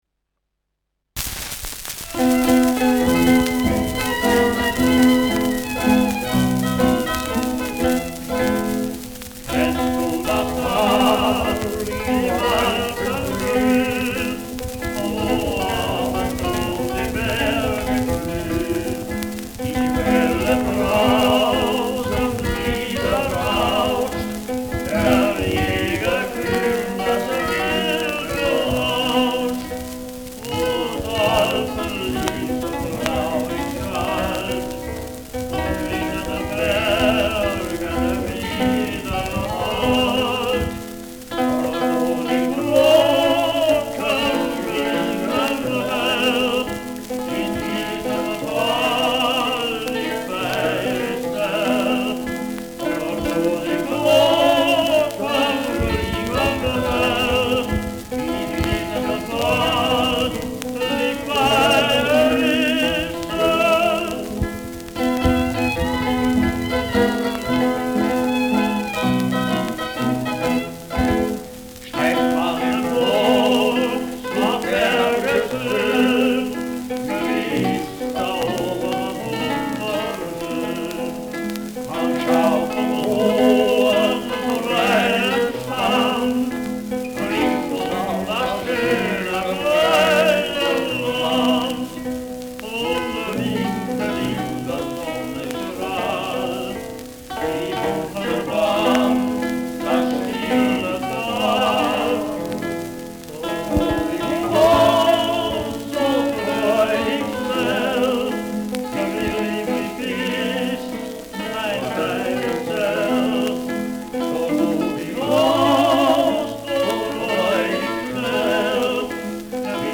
Schellackplatte
[München] (Aufnahmeort)